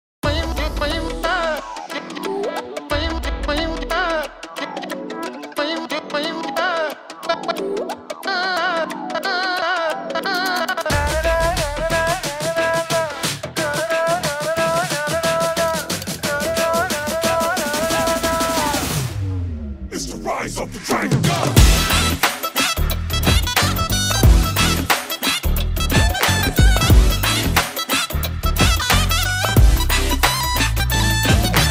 Powerful epic BGM tone for mobile.